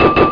1 channel
clank.mp3